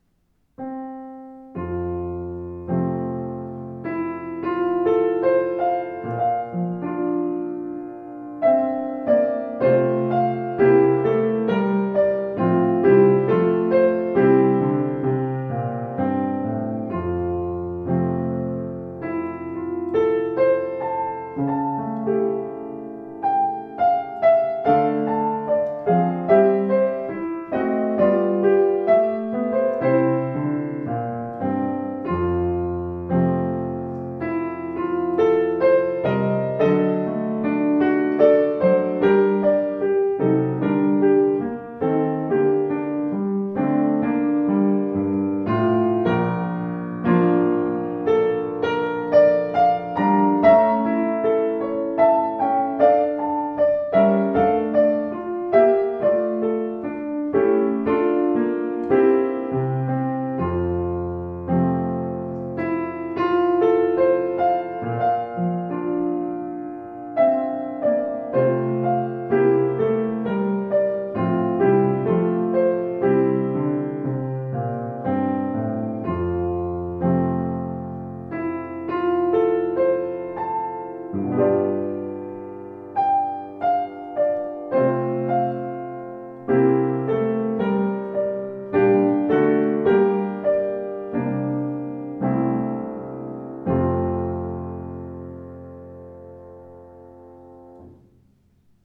Warmer, ausgewogener Klang mit satten Bässen.